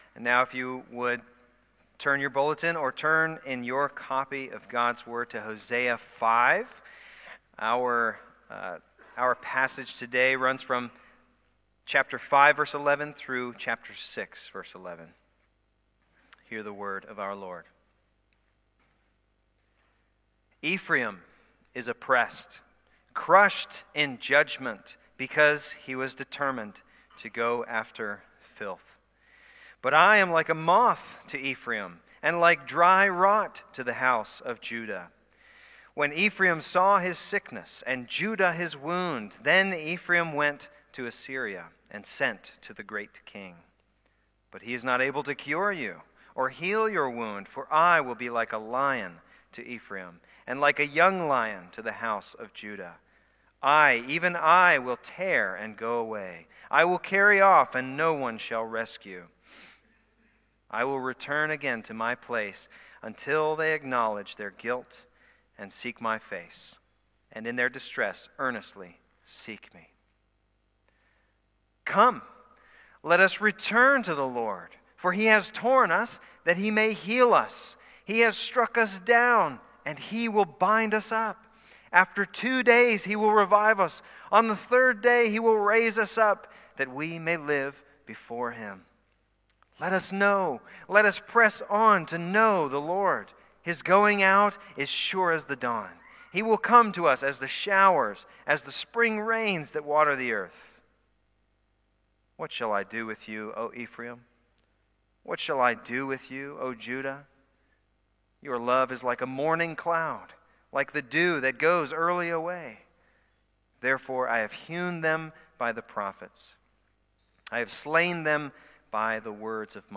Passage: Hosea 5:11-6:11a Service Type: Sunday Morning